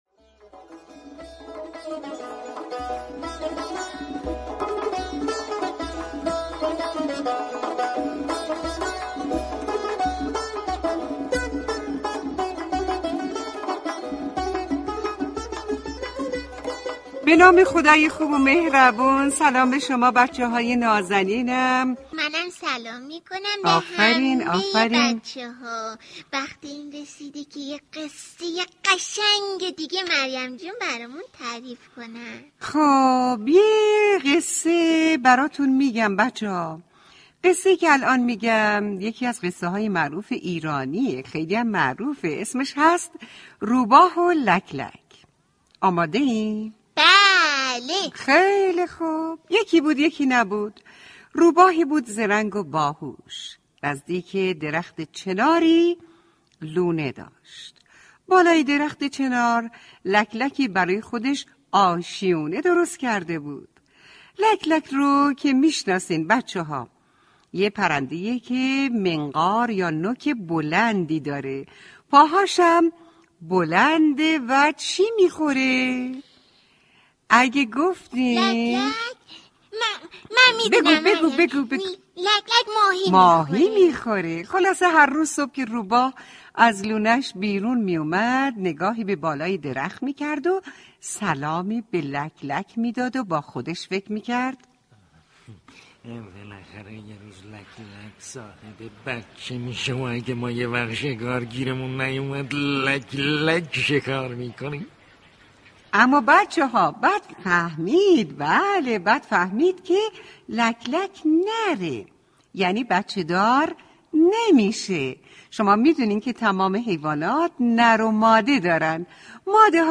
داستان کودکانه داستان کوتاه قصه صوتی